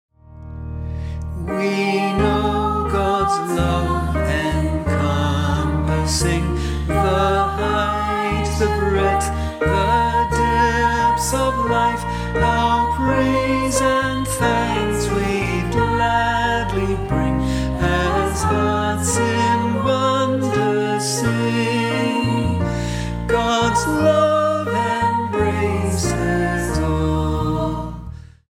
and feature vocals by a range of SA singers